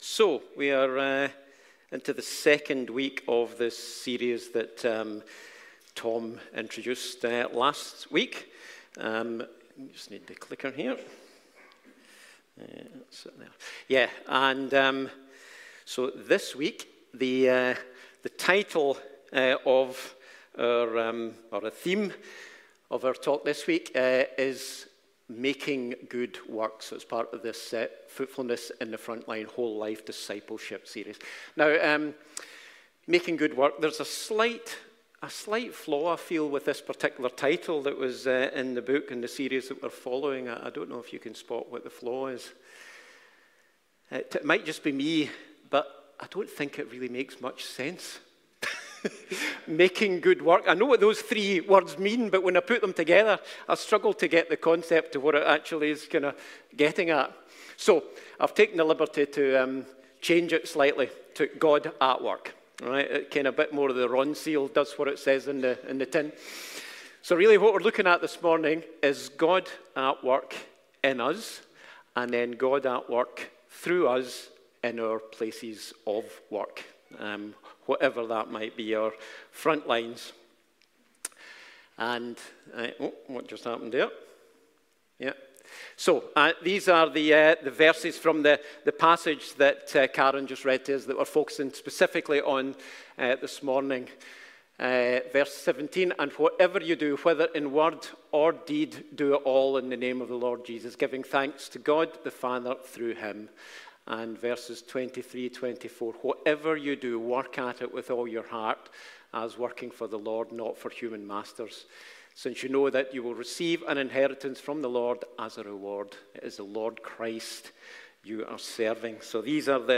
Passage: Colossians 3:1-4, Colossians 3:15-24 Service Type: Sunday Morning